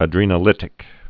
(ə-drēnə-lĭtĭk)